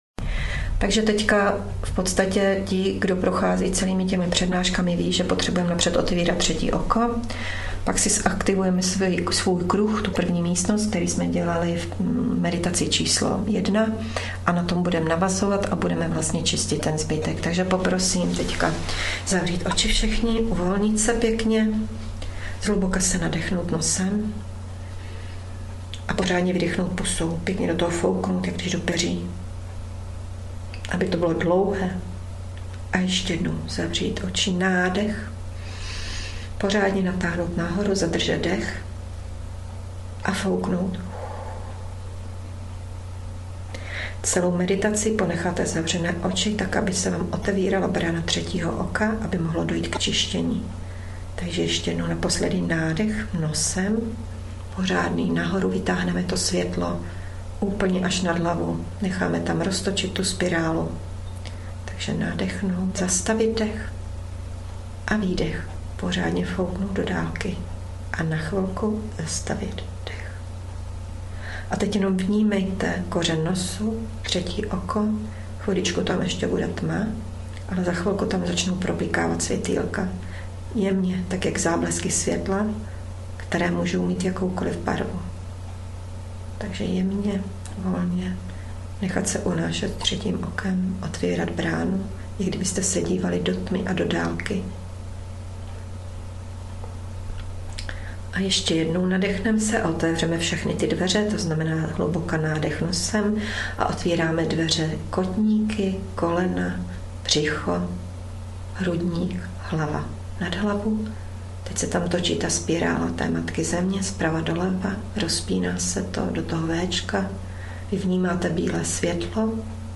Na začátku meditace je úvodní slovo, aby jste pochopili o co jde, a uměli otevřít svou duši směrem ke světlu.